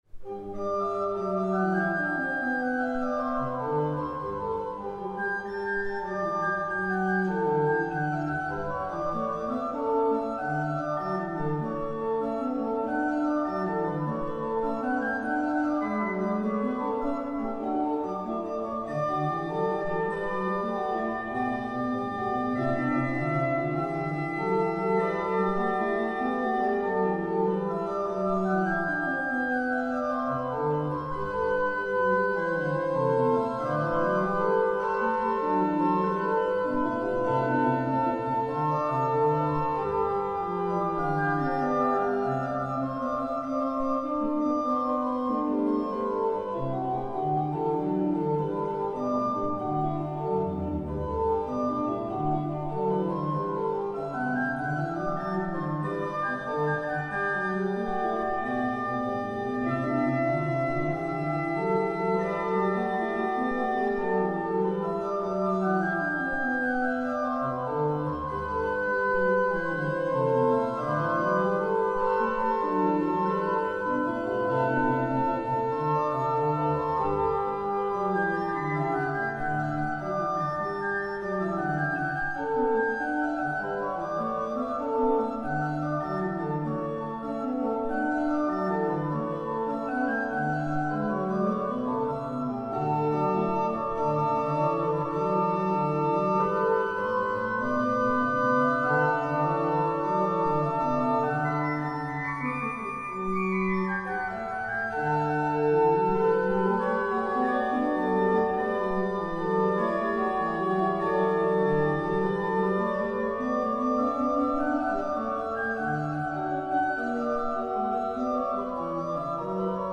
Nürtingen, St. Johannes, ALBIEZ-Orgel
Katholische Pfarrkirche St. Johannes, 72622 Nürtingen